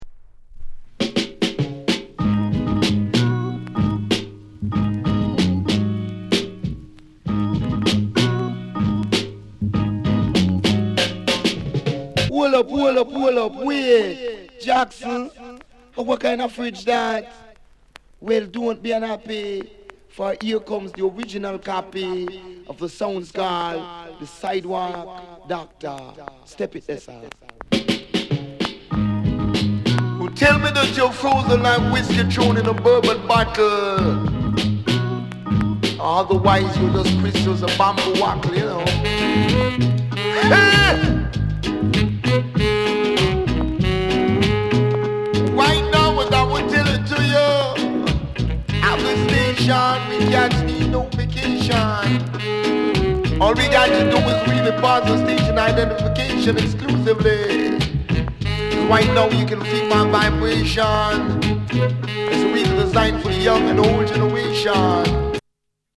KILLER INST